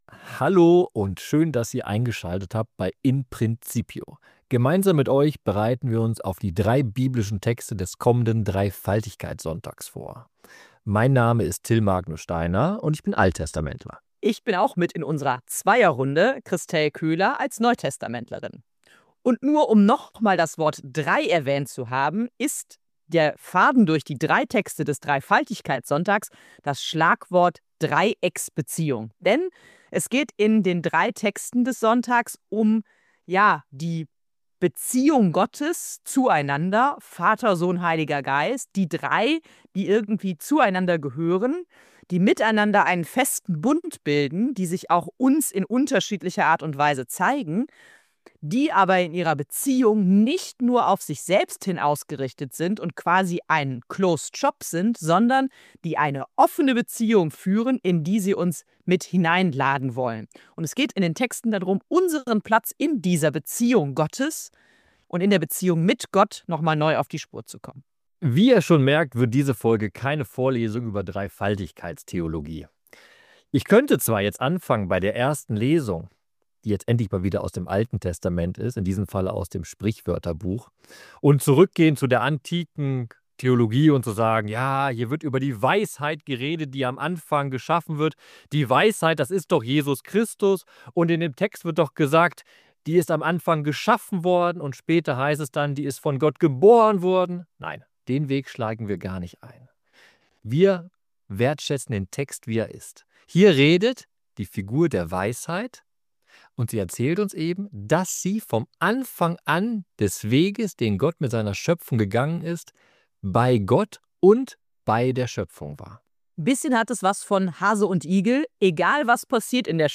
Diskussion